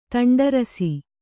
♪ taṇḍarasi